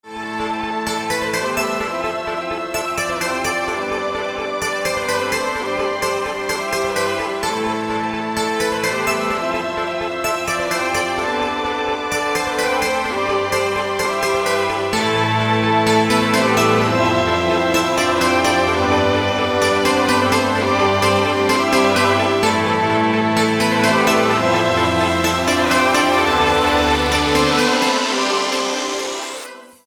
спокойные
инструментальные
мелодия